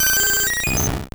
Cri d'Aquali dans Pokémon Or et Argent.